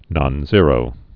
(nŏn-zîrō, -zērō)